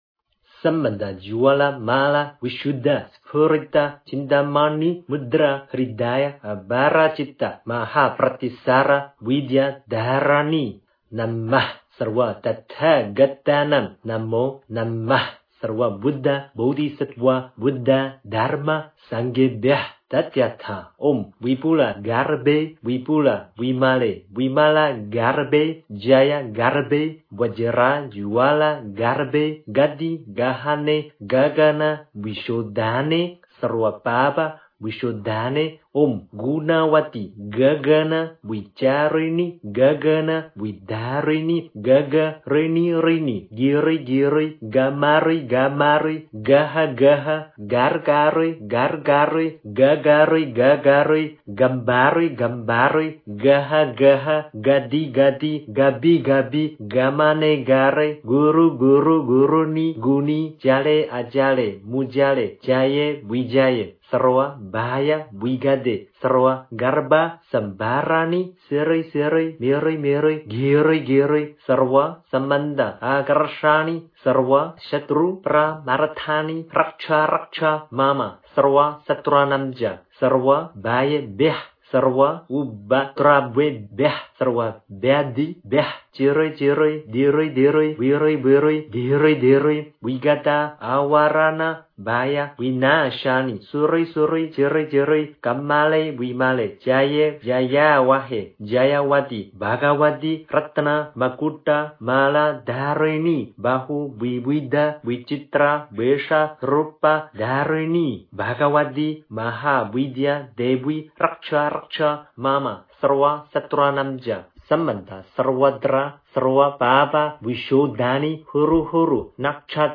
大随求咒 诵经 大随求咒--佛教音乐 点我： 标签: 佛音 诵经 佛教音乐 返回列表 上一篇： 我是佛前的一朵青莲 下一篇： 独乐乐不如众乐乐 相关文章 楞伽经（一切法品第二之一） 楞伽经（一切法品第二之一）--未知... 44.看心--佚名 44.看心--佚名...